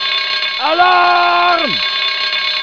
9alarm.wav